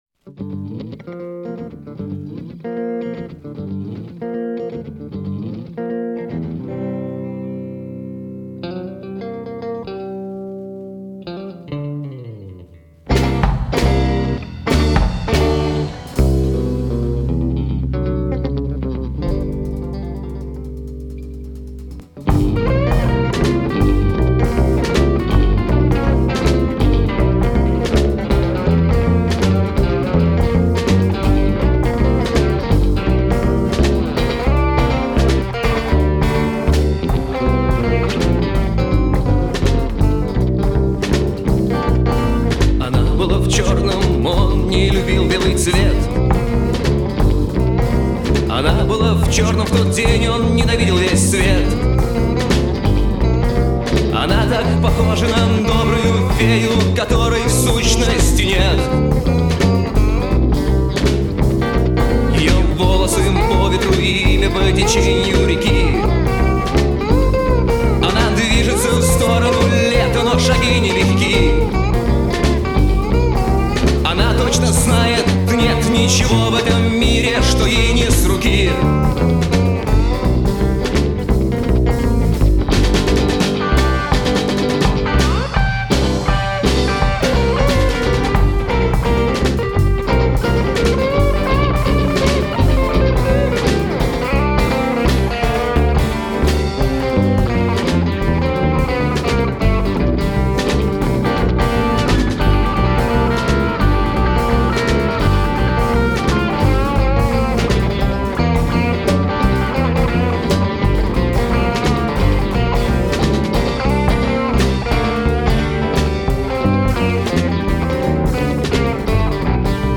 слова, музыка, голос,
ритм-гитара, акустическая гитара,
аккордеон.
ударные, перкуссия, крик (2),